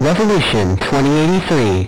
penny haze and ivy voice lines